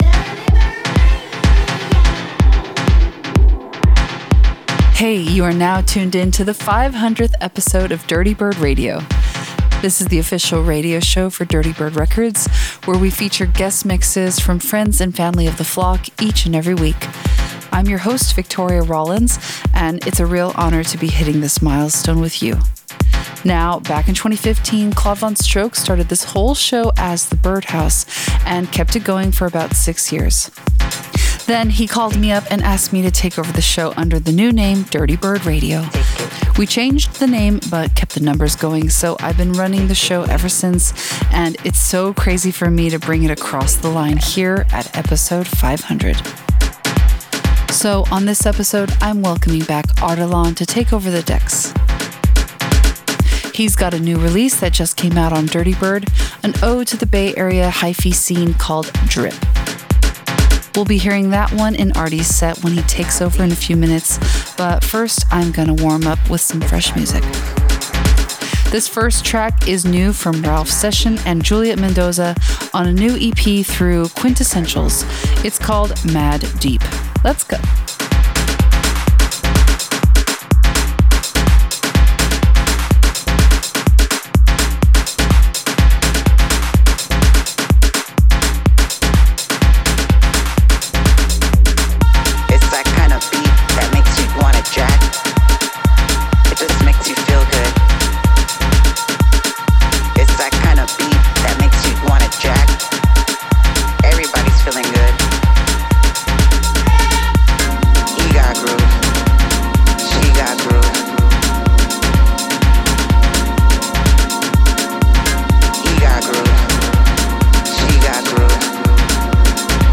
VR warm-up set